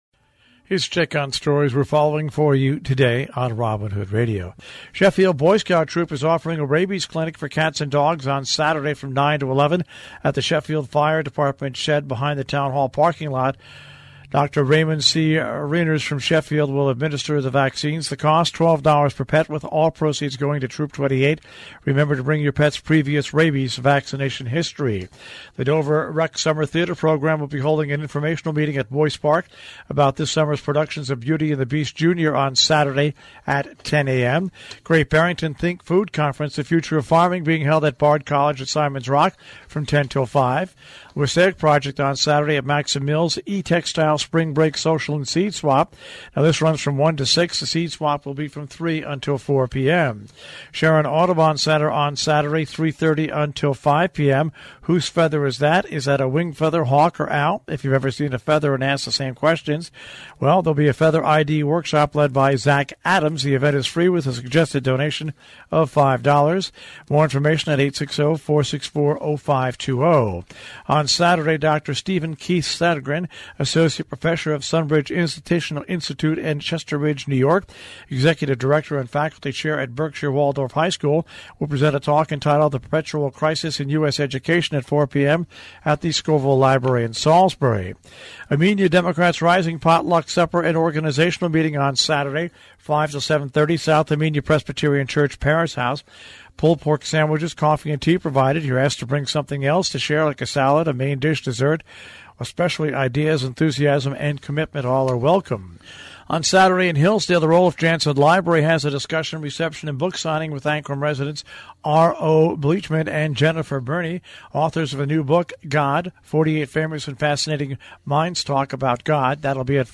WHDD-BREAKFAST-CLUB-NEWS-FRIDAY-APRIL-6.mp3